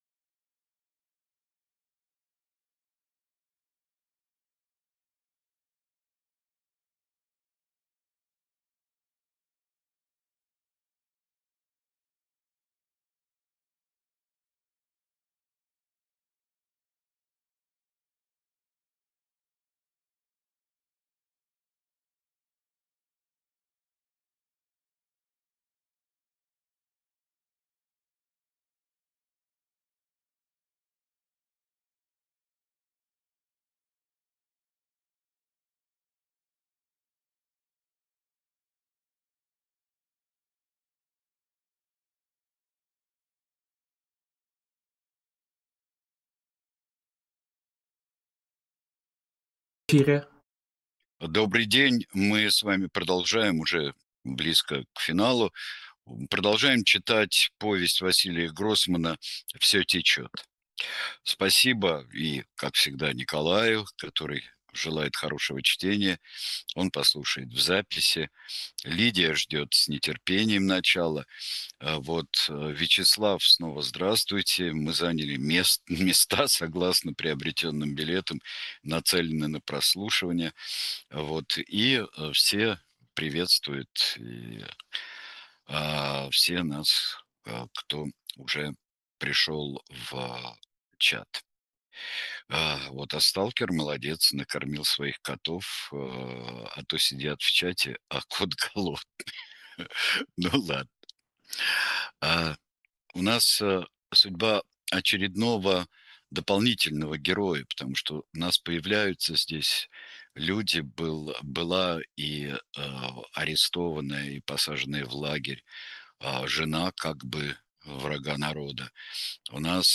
Читает Сергей Бунтман
vsyo-techyot-vasiliya-grossmana.-chast-8.-chitaet-sergej-buntman.mp3